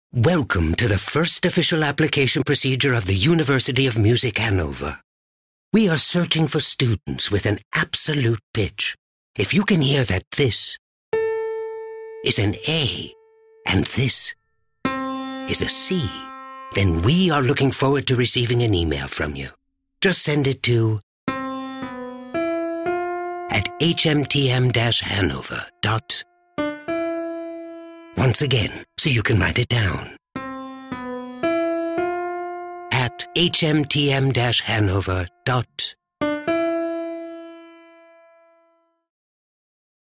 The music notes spell out the school’s email address.
hmtm-hannover-the-absolute-pitch-ogilvy-germany-frankfurt.mp3